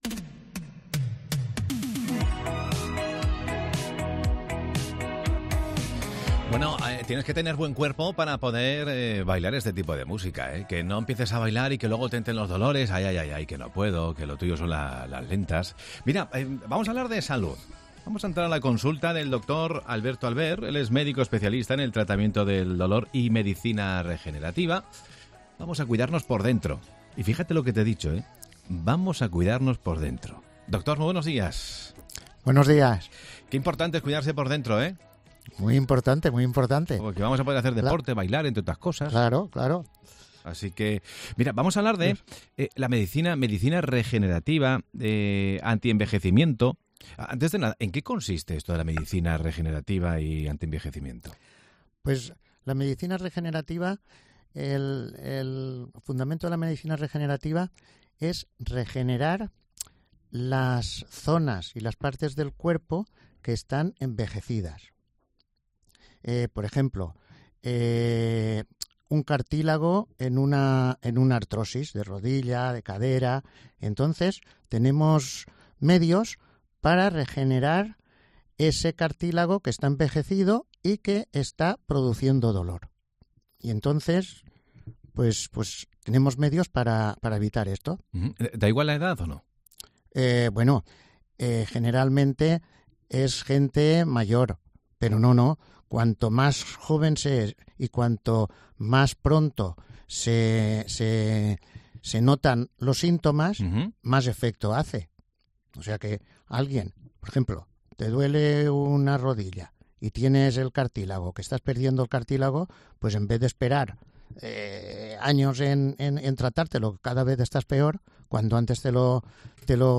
explica en Mediodía COPE Alicante los beneficios que aporta la medicicina regenerativa y antienvejecimiento.